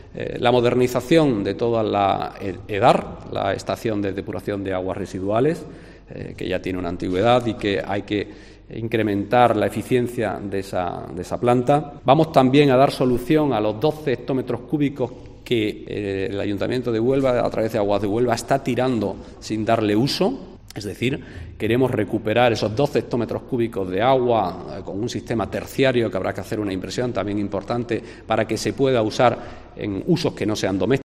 Felipe Arias, portavoz equipo de Gobierno en Huelva